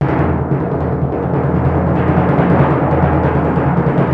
TIMP 6.WAV